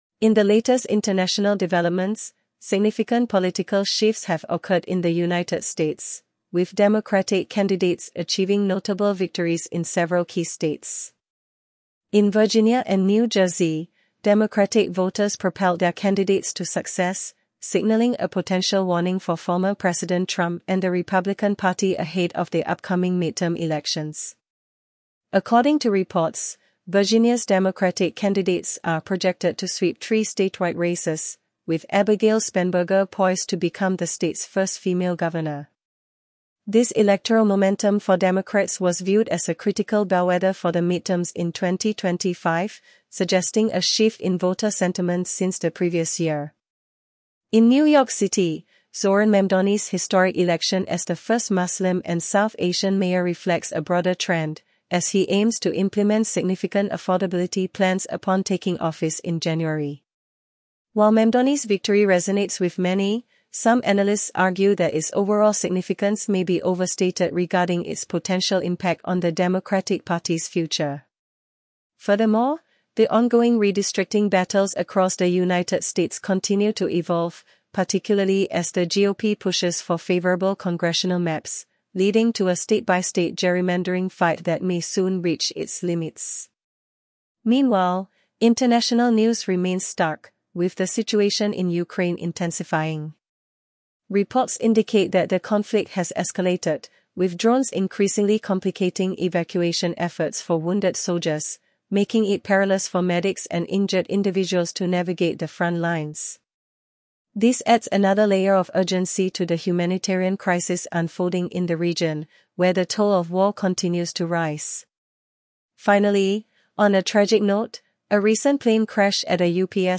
World News Summary
World News